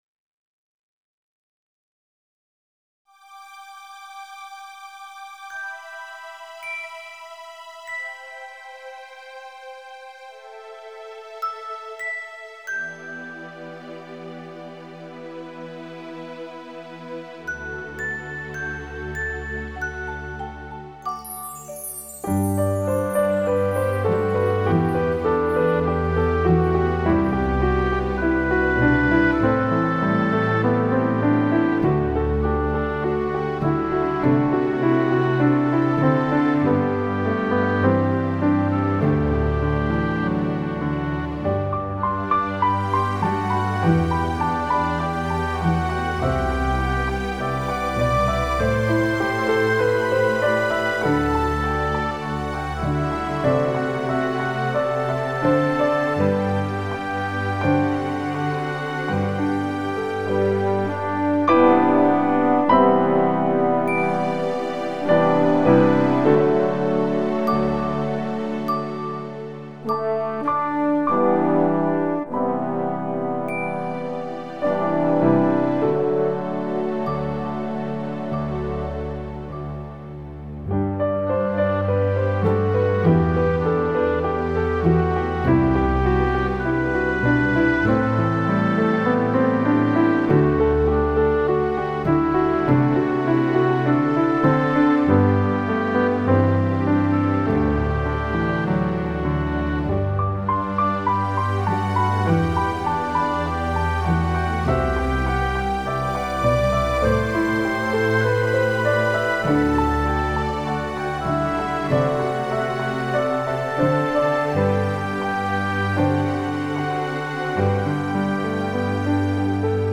MP3 Slow